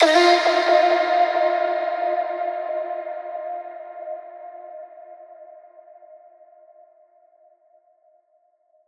VR_vox_hit_oh_F.wav